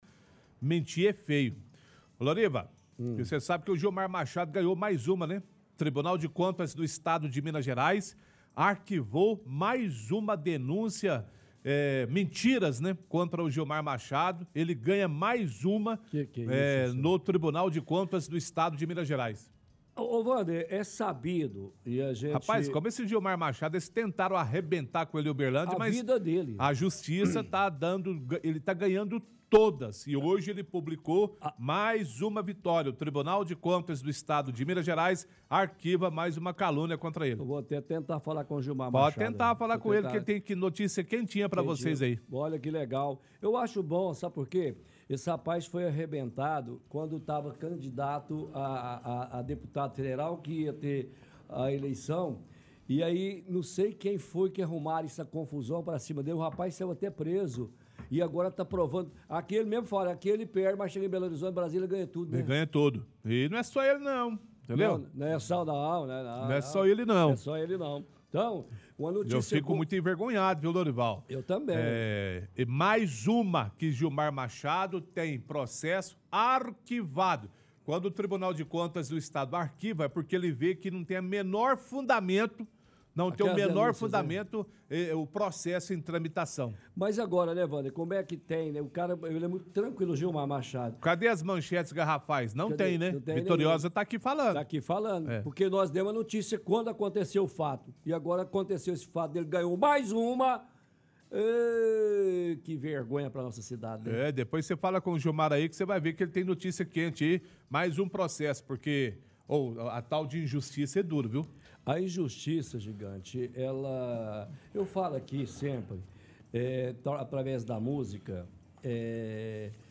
– Gilmar Machado explica sobre o processo que foi arquivado. Comenta da calamidade pública que o prefeito abriu na época.